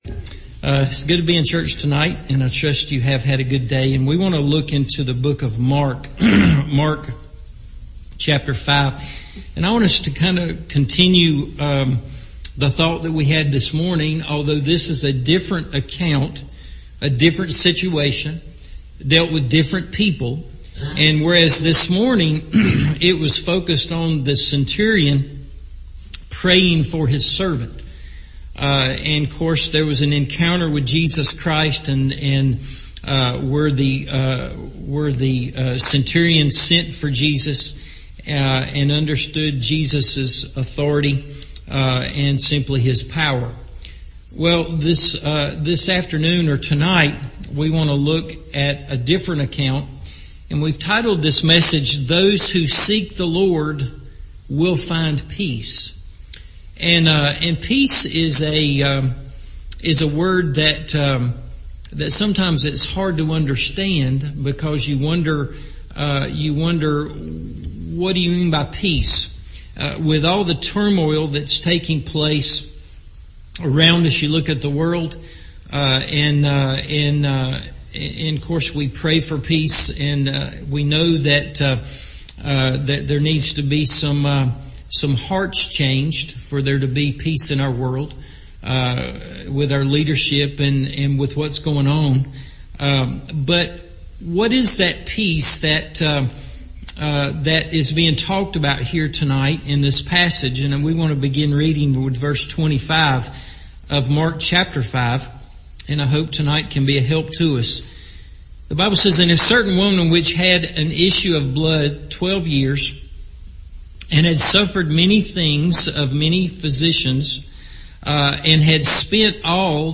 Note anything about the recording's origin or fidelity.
Those Who Seek the Lord Will Find Peace – Evening Service